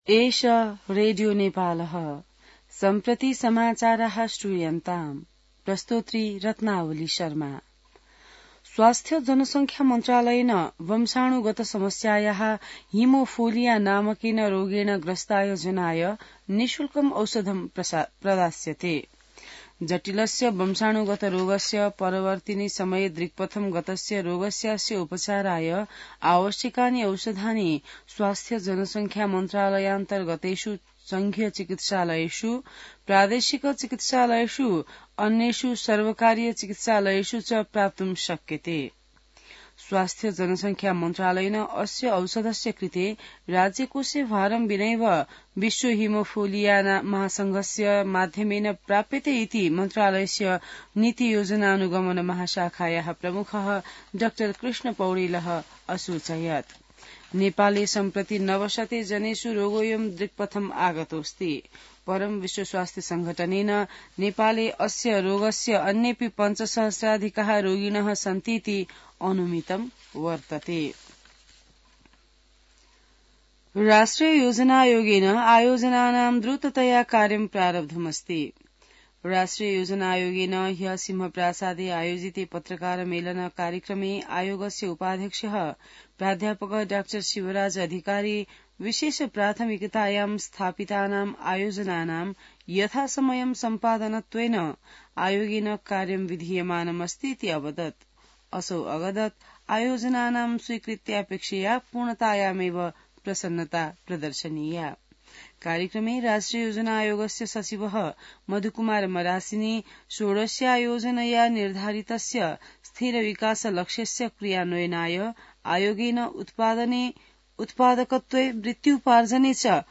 संस्कृत समाचार : १२ माघ , २०८१